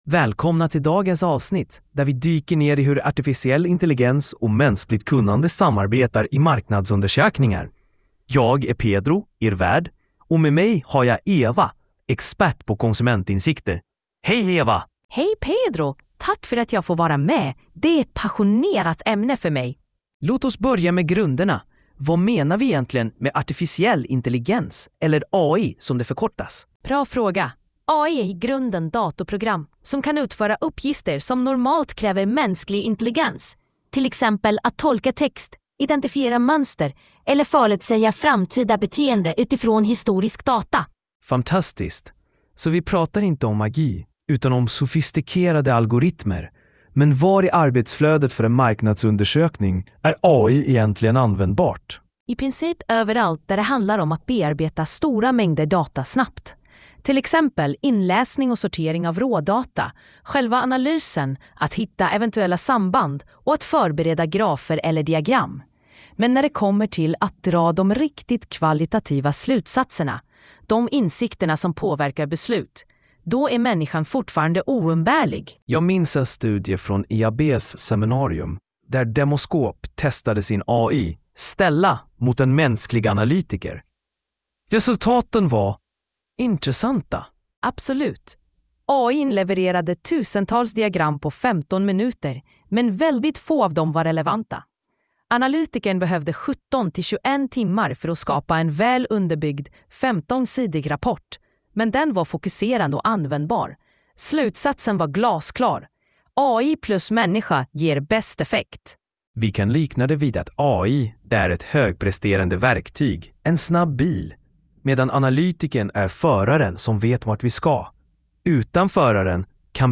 Lyssna på avsnittet genom en AI-genererad podcast: